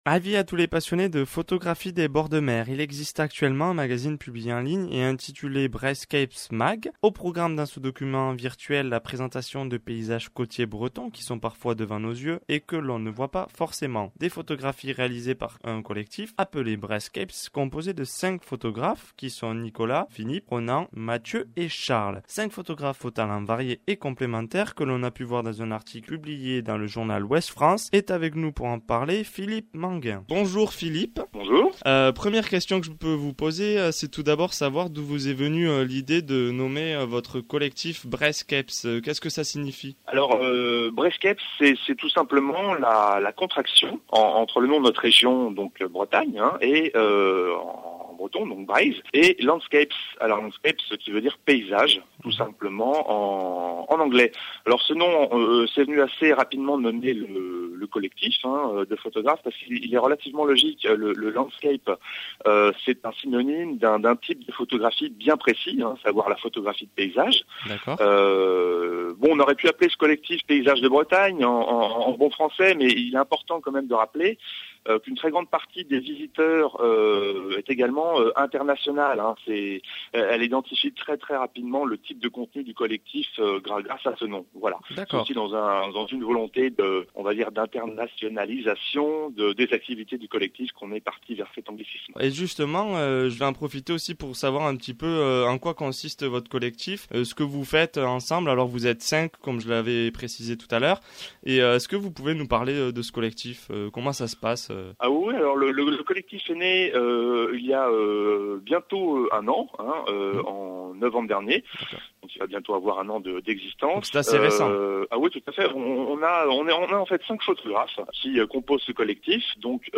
Une interview sur RADIO LASER difusée en Novembre 2013 afin de présenter le collectif de photographes dont je fais partie !